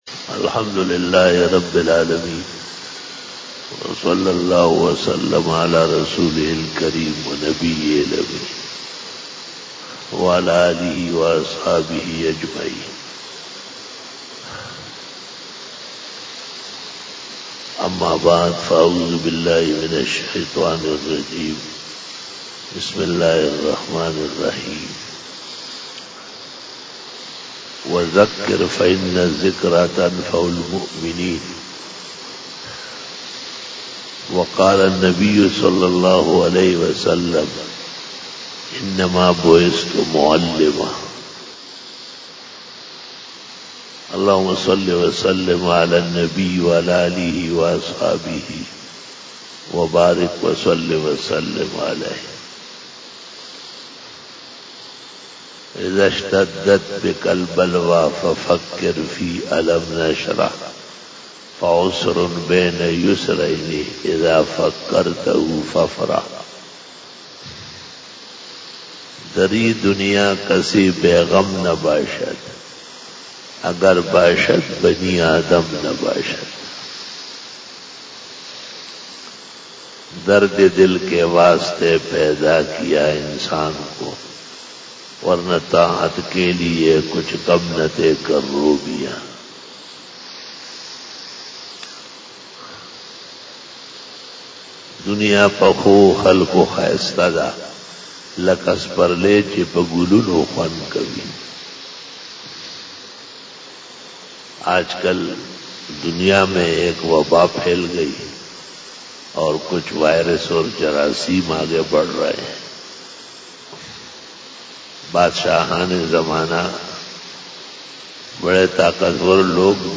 12 BAYAN E JUMA TUL MUBARAK 20 March 2020 ( 24 Rajab 1441H)